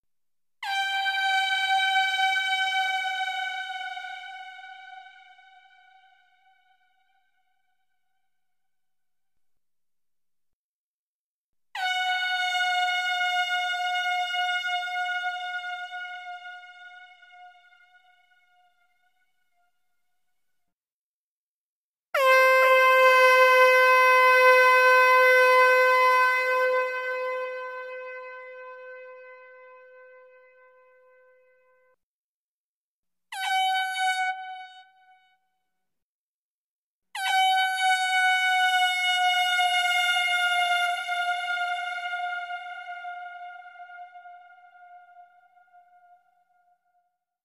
Air Horn | Sneak On The Lot